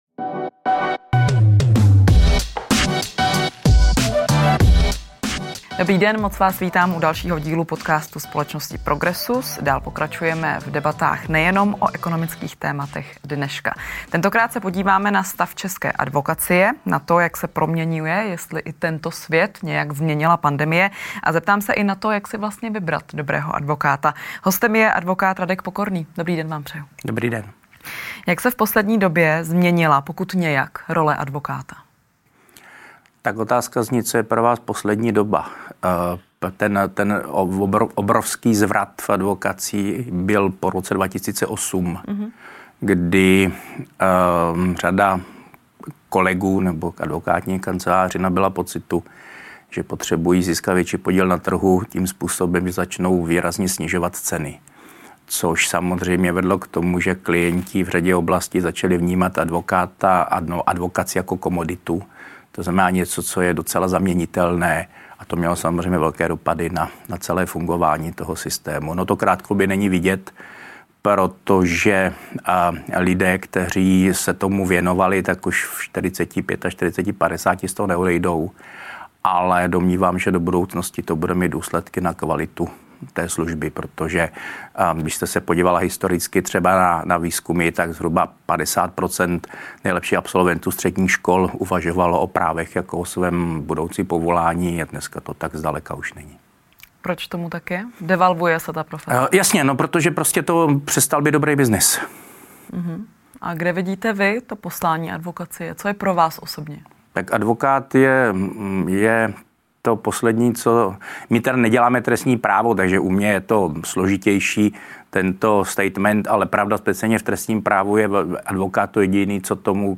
Další díl podcastu přináší rozhovor s advokátem